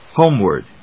/hóʊmwɚd(米国英語), hˈəʊmwəd(英国英語)/